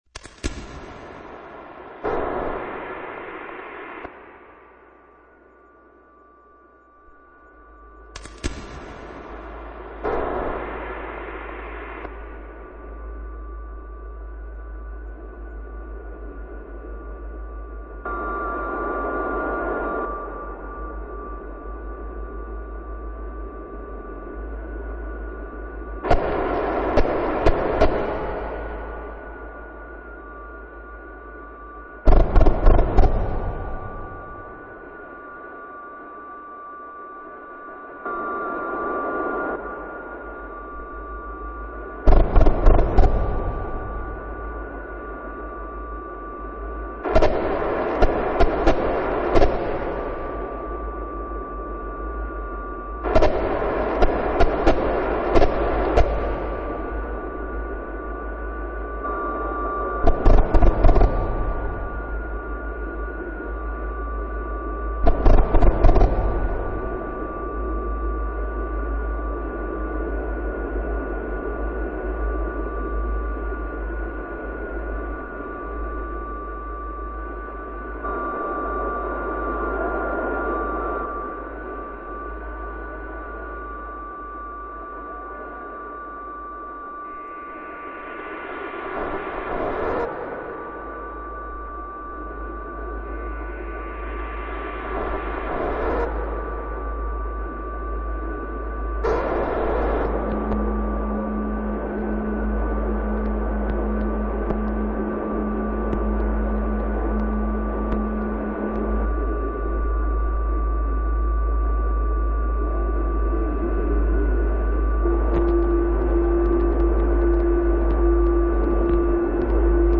This musical work for shortwave radio and electron...